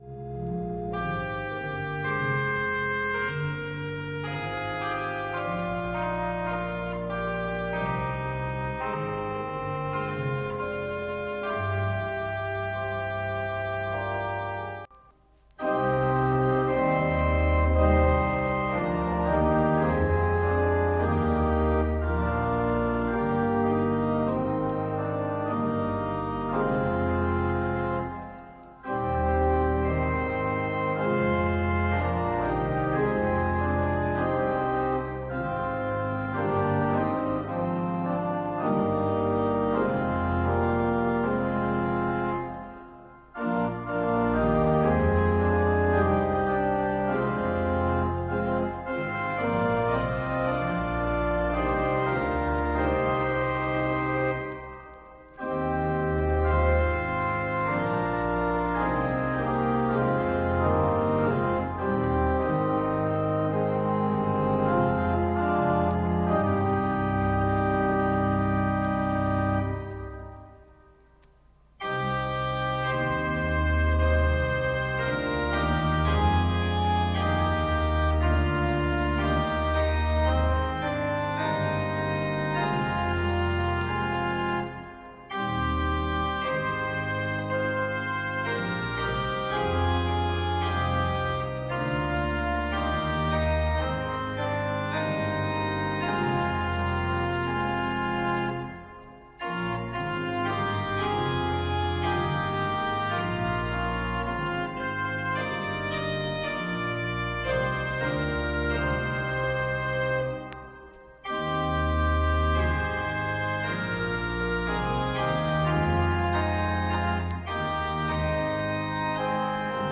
Hier sind die Orgelbegleitungen für die Choräle zum Mitsingen: